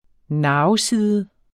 Udtale [ ˈnɑːw- ]